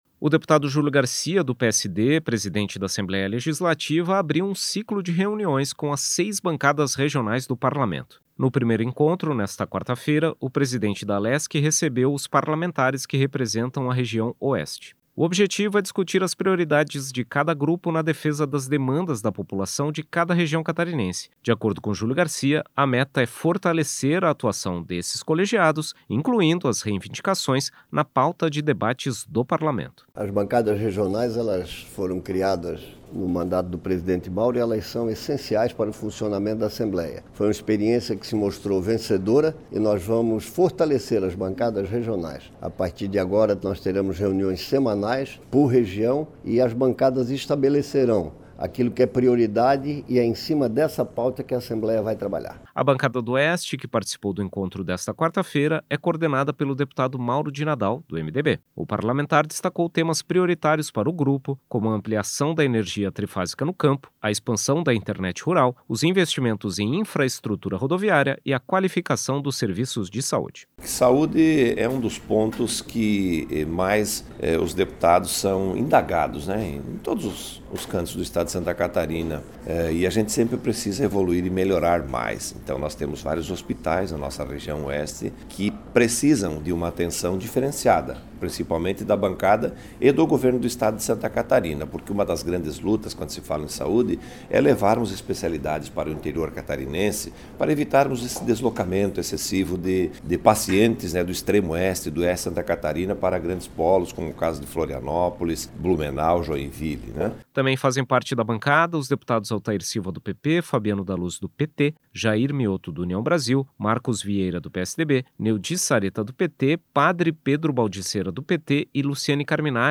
Entrevistas com:
- deputado Julio Garcia (PSD), presidente da Assembleia Legislativa;
- deputado Mauro de Nadal (MDB), coordenador da Bancada do Oeste da Assembleia Legislativa.